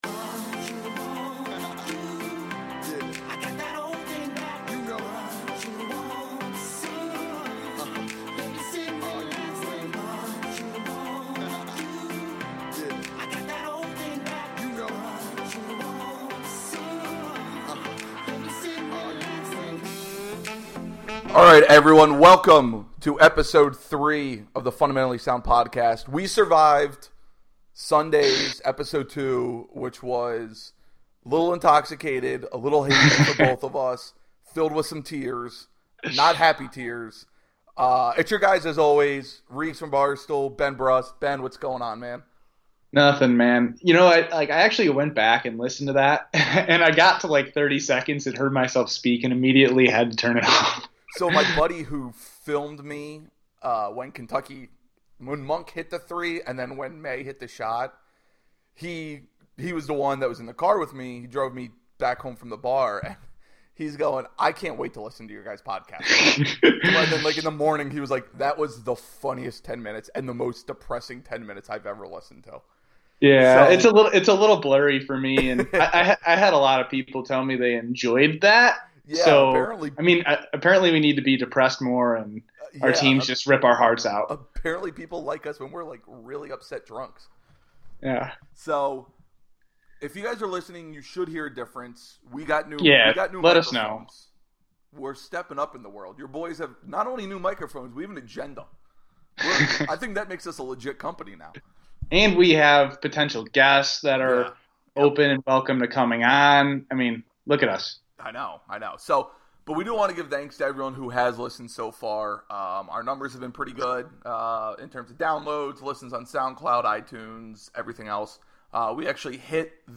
with a happier tone this time as they bounce back from buzzer beaters to talk Final Four. Who are the most important players left?